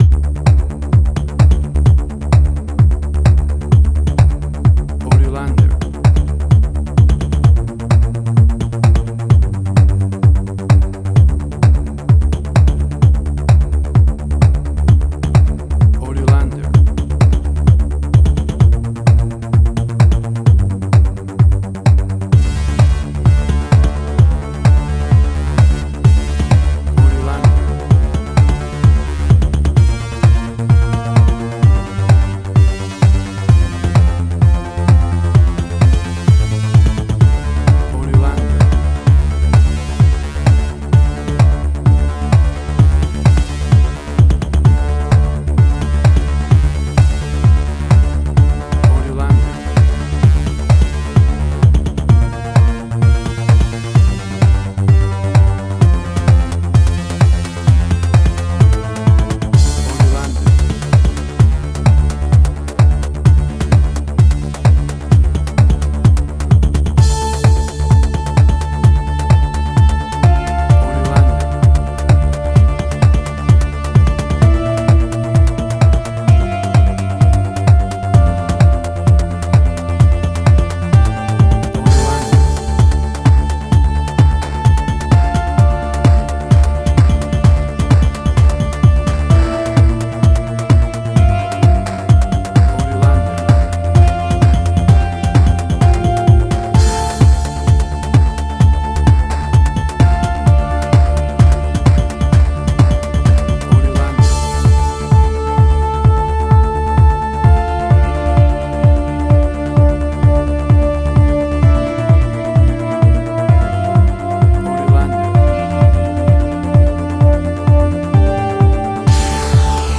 House.
Tempo (BPM): 128